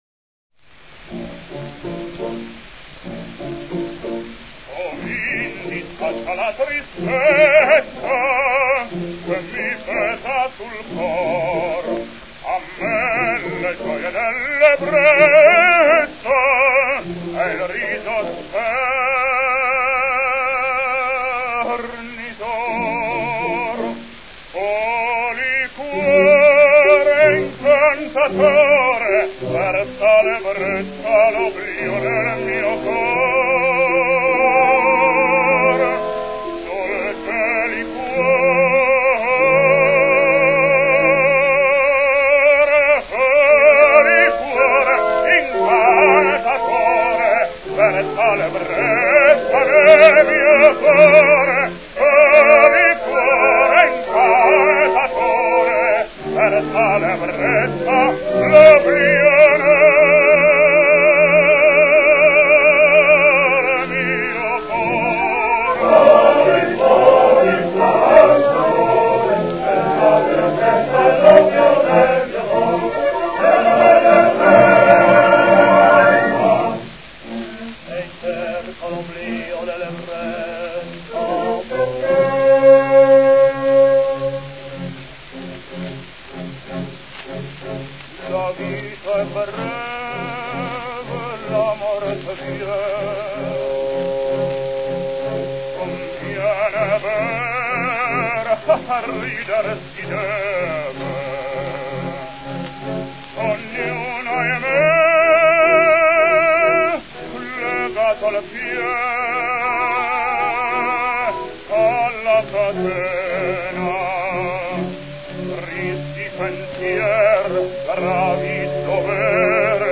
Italian baritone, 1877 - 1953
Ruffo’s voice had something of a force of nature about it, something animalistic even.
His strength was the unbelievably rich volume in the highest range (which occasionally reached tenorial regions), the immediacy of tonal formation and thus an astonishing ability in “parlando” singing. This was offset by a certain huskiness in the lower range - a deficit that increased during the course of his career.